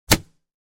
Hitmarker-sound-effect.mp3